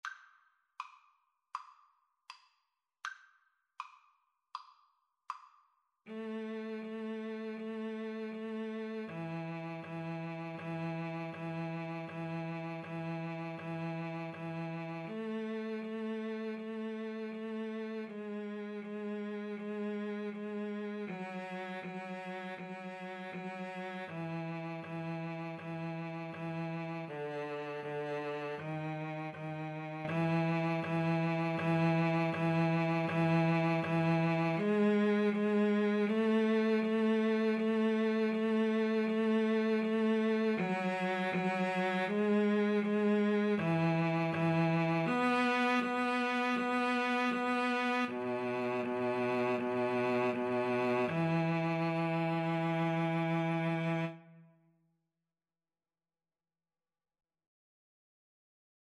Free Sheet music for Violin-Cello Duet
ViolinCello
A major (Sounding Pitch) (View more A major Music for Violin-Cello Duet )
andante Largo
Classical (View more Classical Violin-Cello Duet Music)